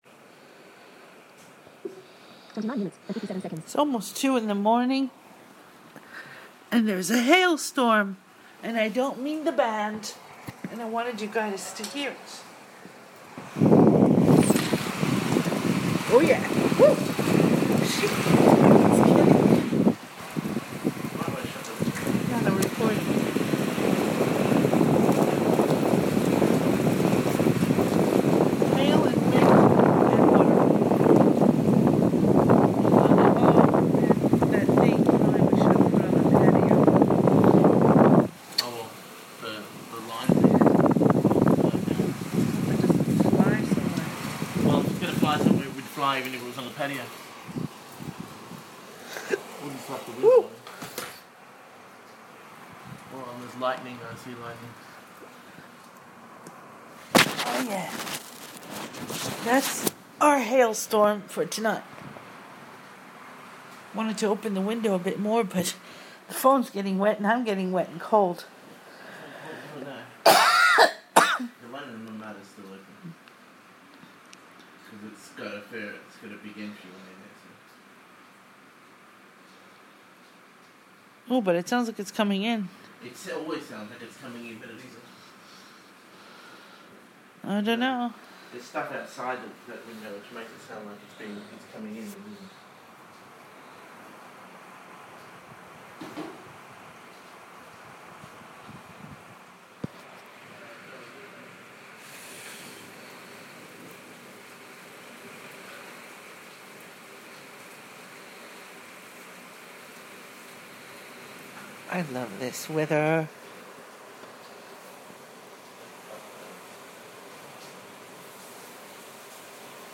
A very late night hailstorm, and I don't mean the band